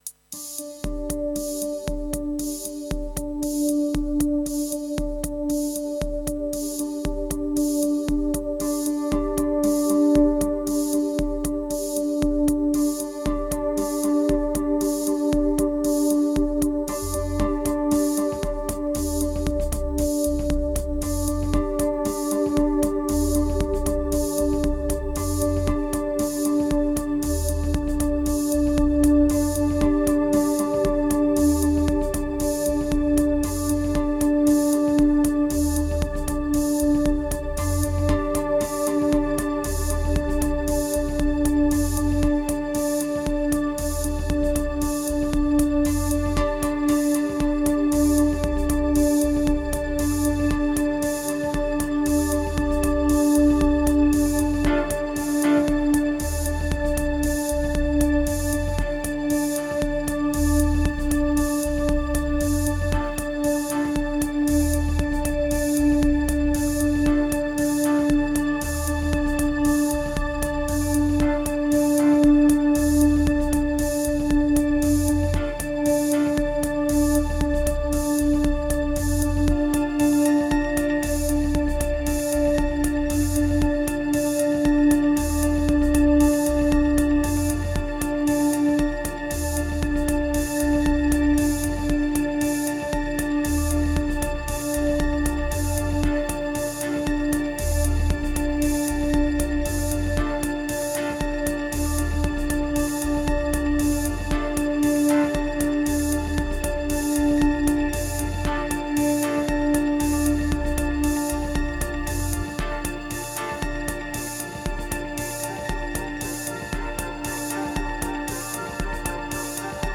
Further downwards, aiming for ambient, ends up